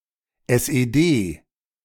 The Socialist Unity Party of Germany (German: Sozialistische Einheitspartei Deutschlands, pronounced [zotsi̯aˈlɪstɪʃə ˈʔaɪnhaɪtspaʁˌtaɪ ˈdɔʏtʃlants] ; SED, pronounced [ˌɛsʔeːˈdeː]